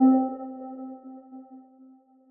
Pluck - Ratchet.wav